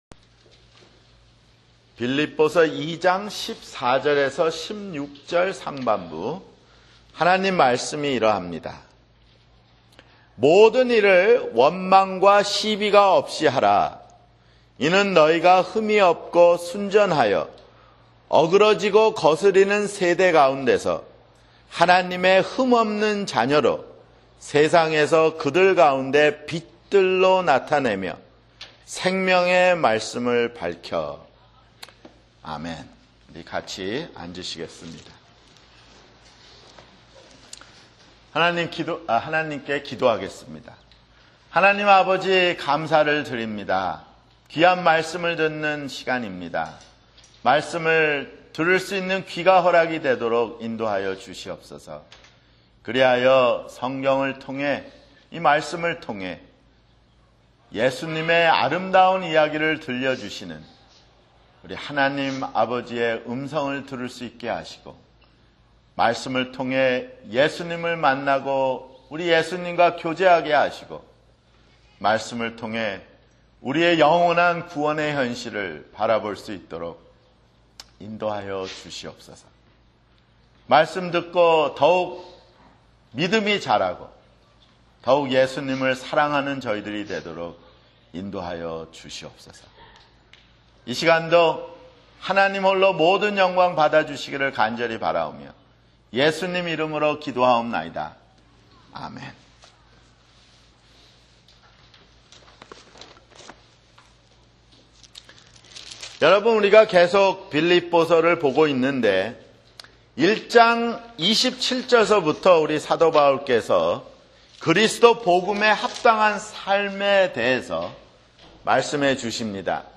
[주일설교] 빌립보서 (32)